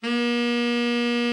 TENOR 16.wav